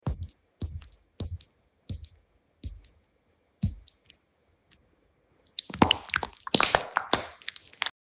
ASMR RELAXING FOR SLEEP Sound Effects Free Download